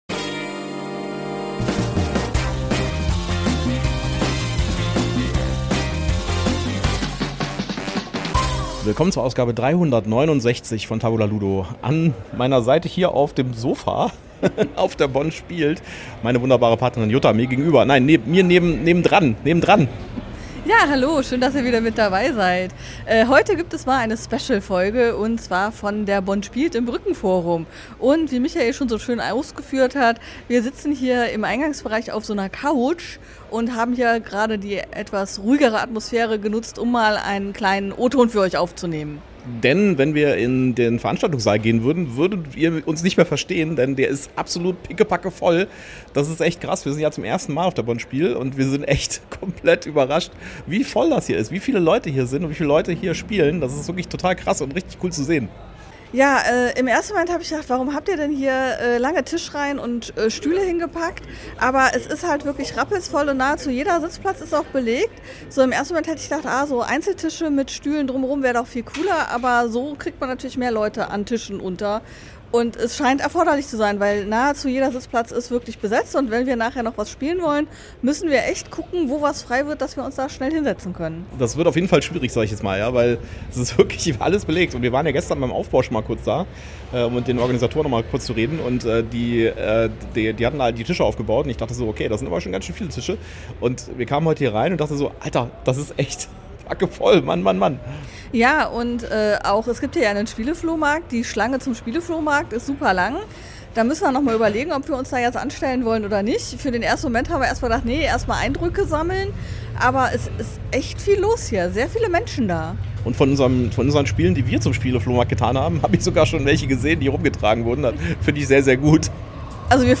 Auch diese Woche haben wir wieder eine pickepacke volle News-Show für euch. Mit "Mistborn" bringt Asmodee die Deutsche Version des Deckbau-Spiels in Brandon Sandersons "Nebelgebornen"-Serie, Spielworxx hat Ärger wegen der "Arcs"-Übersetzung und Stonemaier Games hat eine ganze Wagenladung neuer Spiele angekündigt.